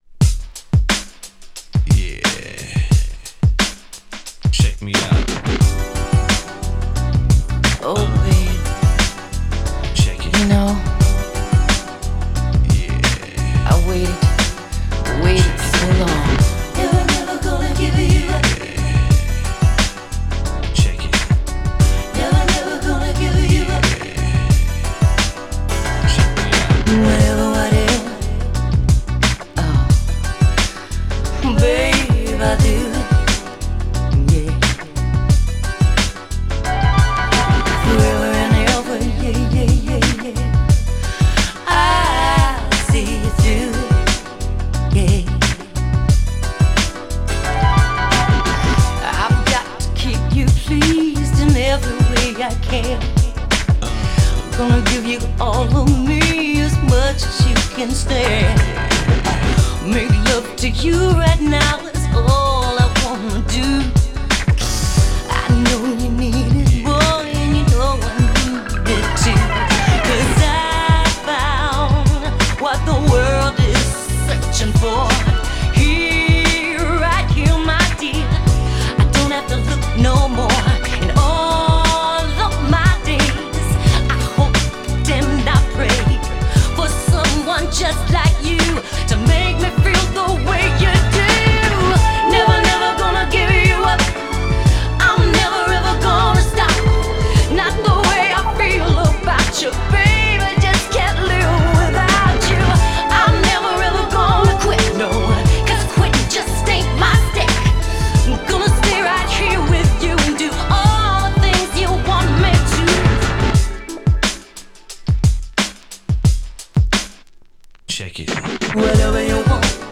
MAIN MIX収録!!
GENRE House
BPM 101〜105BPM